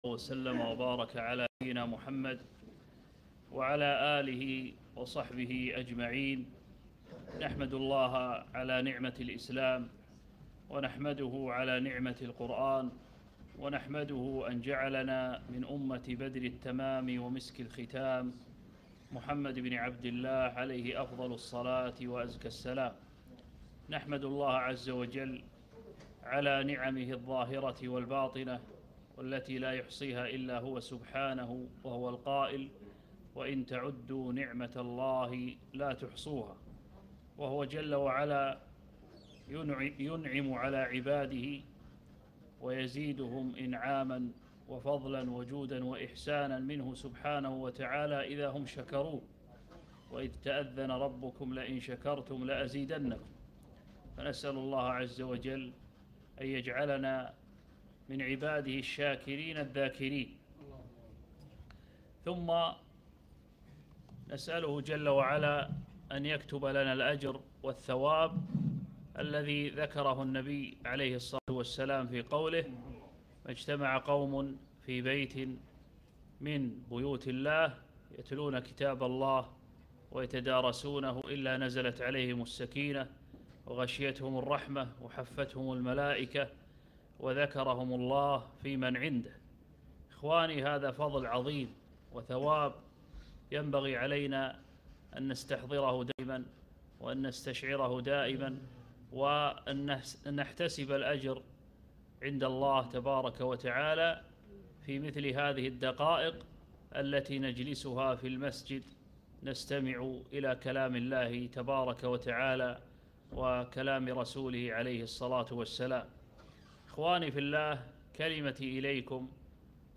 محاضرة - حقيقة الصوم